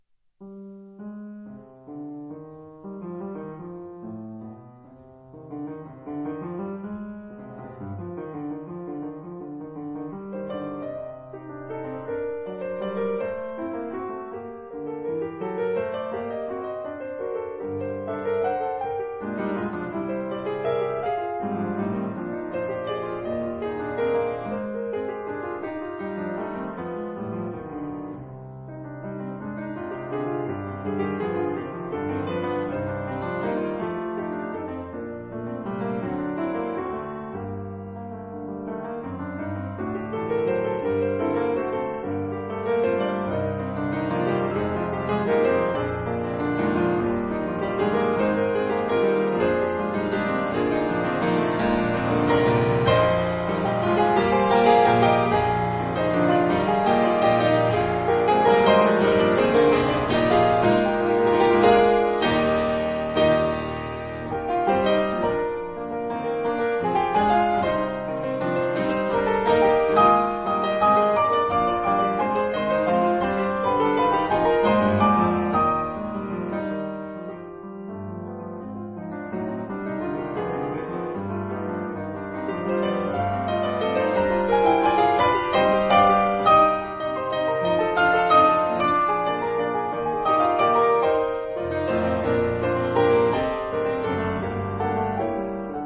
如果不熟悉曲目，那一定會覺得這是鋼琴曲。
活脫就是寫給鋼琴的賦格！